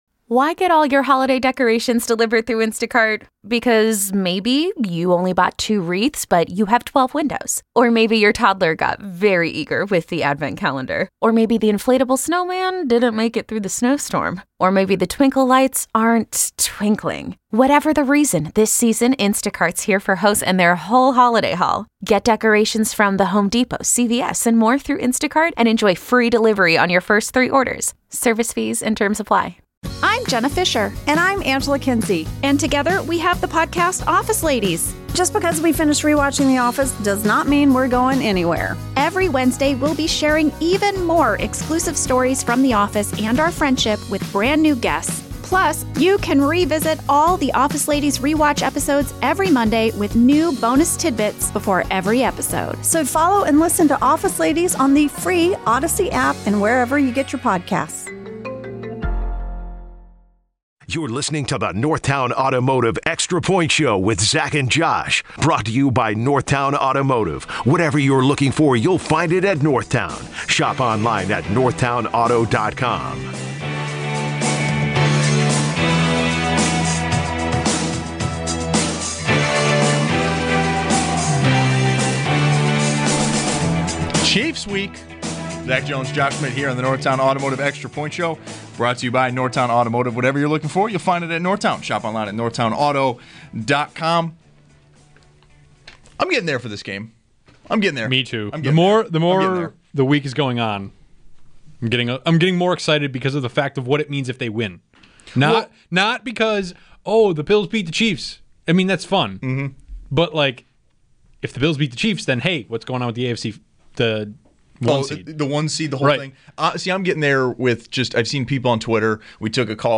Buffalo Bills head coach Sean McDermott joins the show.